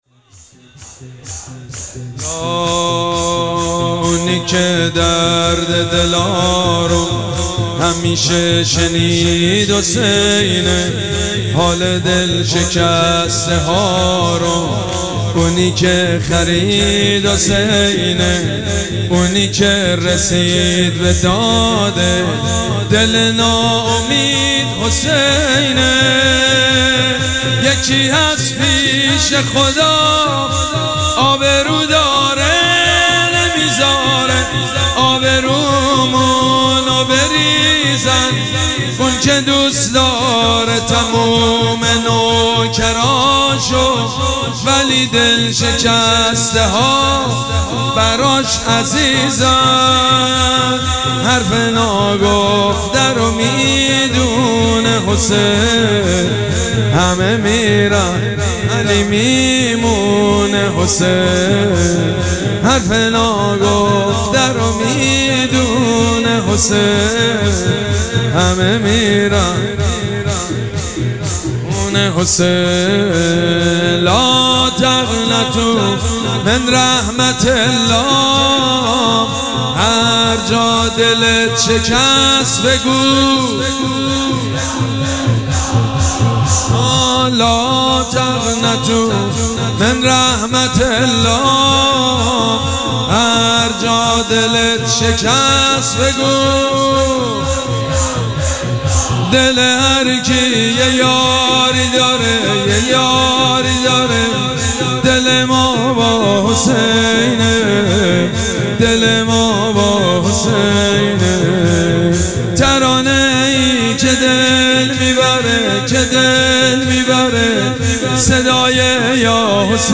شب سوم مراسم عزاداری اربعین حسینی ۱۴۴۷
شور
مداح
حاج سید مجید بنی فاطمه